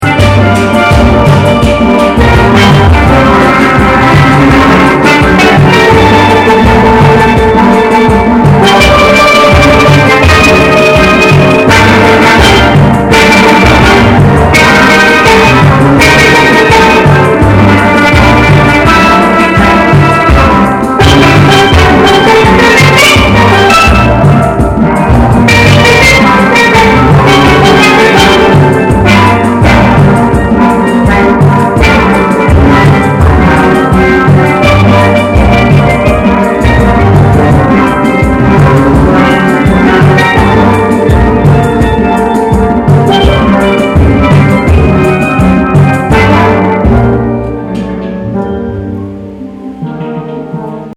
The next you’re in deepest Kendall, in a warehouse across the street from the Tamiami Airport, with a handsome Trinidadian gentleman handing you a pair of felt-tipped mallets and gesturing toward an array of six steel oil drums.
Two expert musicians were already kicking out the bass in what looked like a dance.
Sounds:  I did an iPhone field recording of the song I got to
21st-Century-Steel-Orchestra.mp3